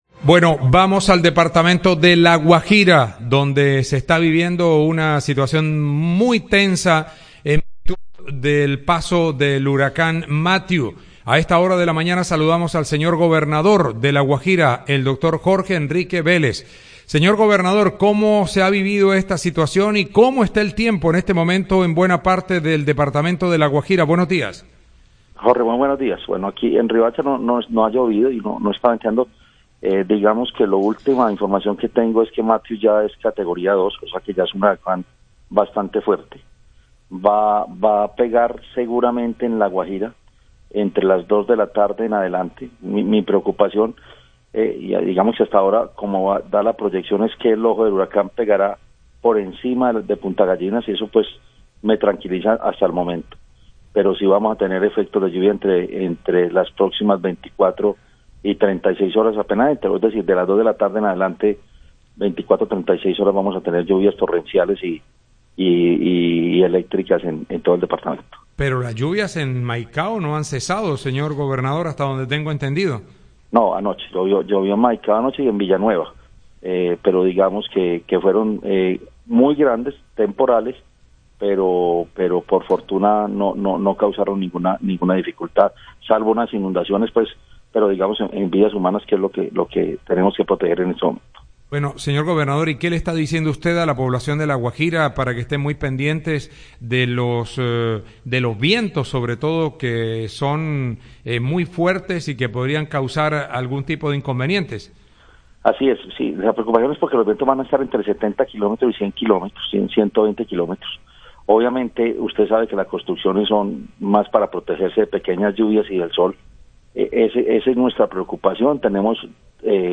El gobernador (e) de La Guajira, Jorge Enrique Vélez, reiteró que se mantiene la alerta roja por el coletazo del Huracán Matthew.